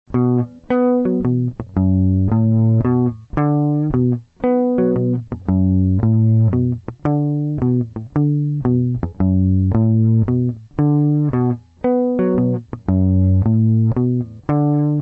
Motiv Audio Beispiel: